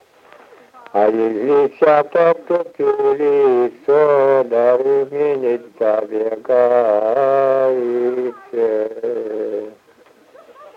Мягкое цоканье (совпадение литературных аффрикат /ц/ и /ч’/ в мягком /ц’/)
/а-н’е-в’и”-д’е-л’ии у-моо”-лоо-ц’а по-йеее”-здо-чк’и:/